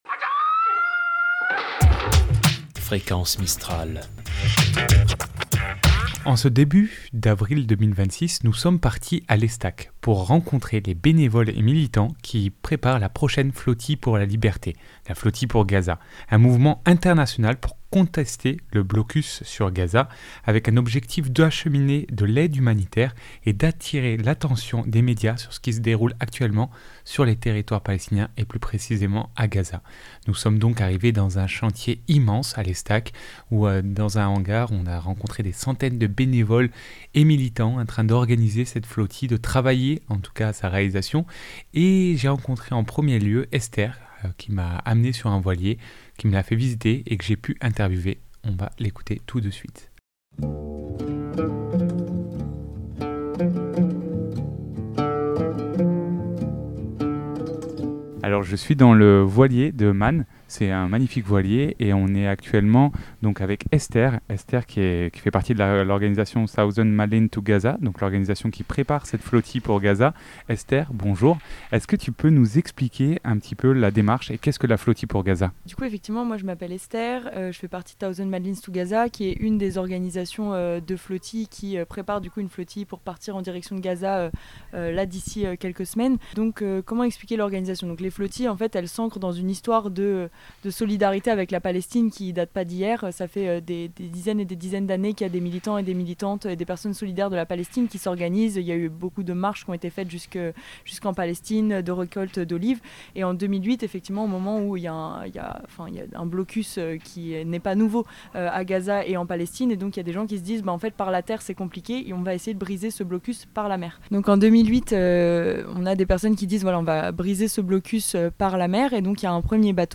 Reportage Flotille pour Gaza.mp3 (27.66 Mo)